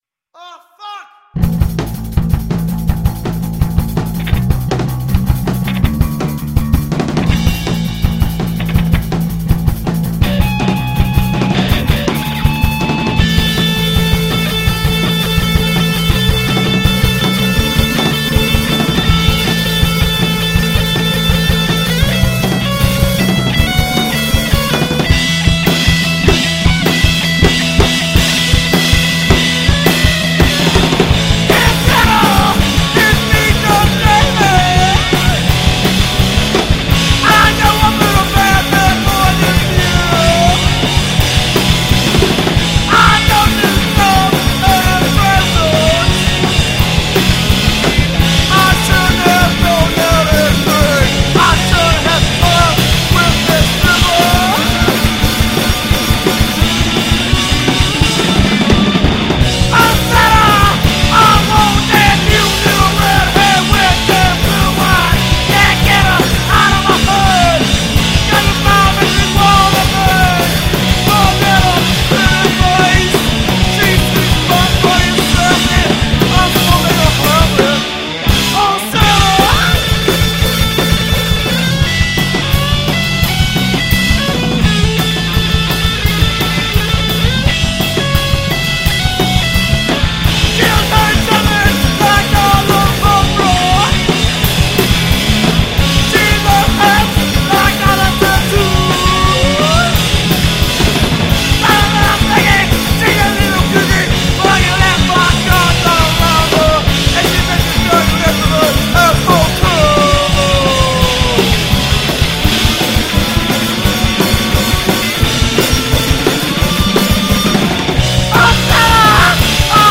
tripped-out punk/ noise fusion